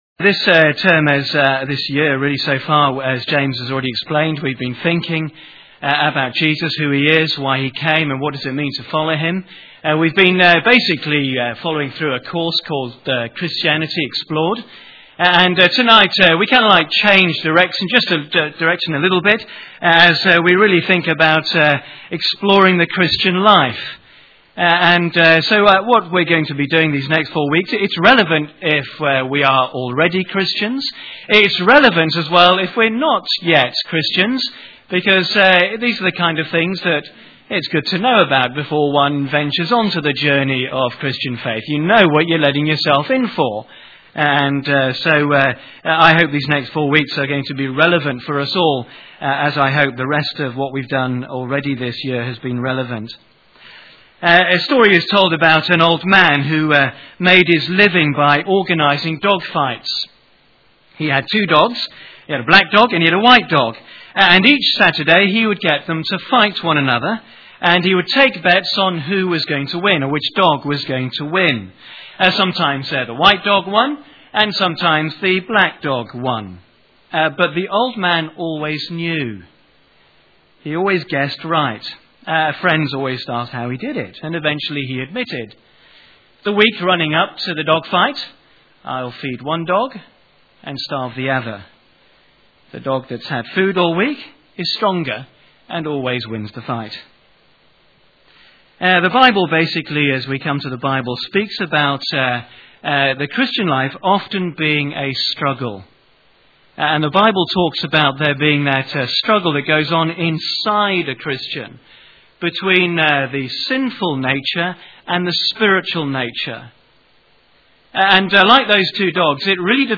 Bible Talks • Christ Church Central • Sheffield